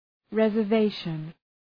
{,rezər’veıʃən}